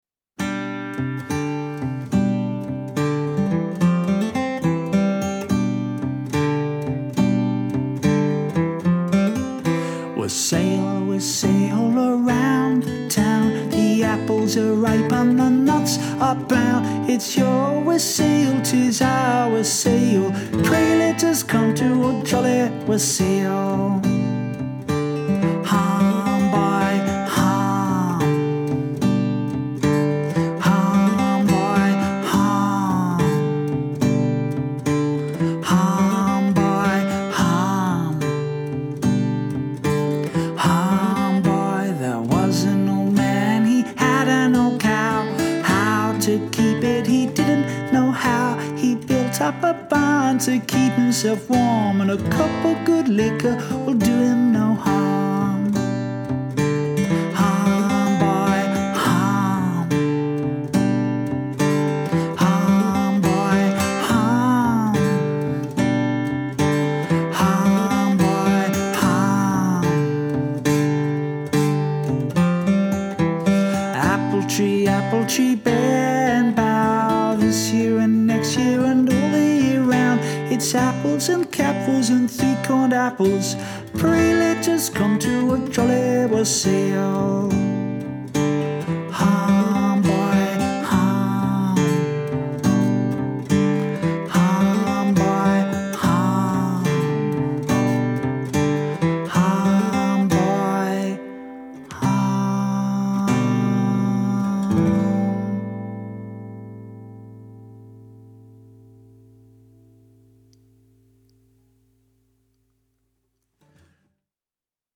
This is a lovely wassail from the West Country. There are two parts to the song & my performance above is the first.